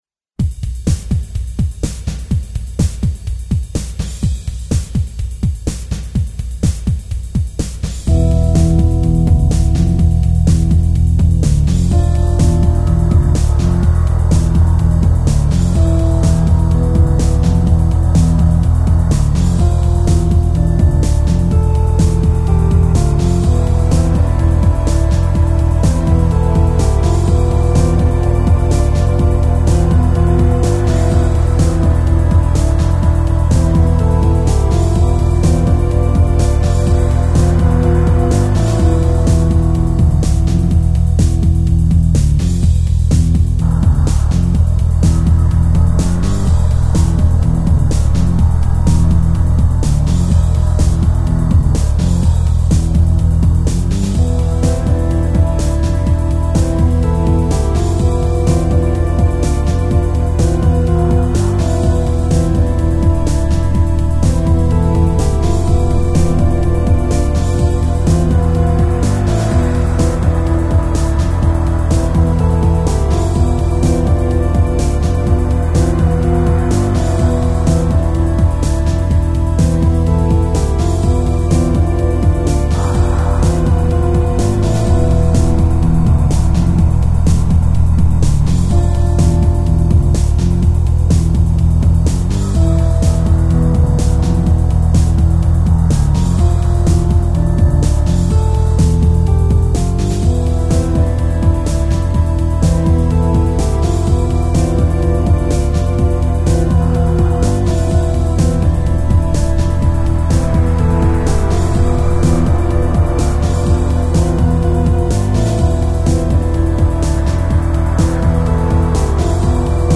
Tech Rock